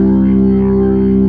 55O-ORG02-C1.wav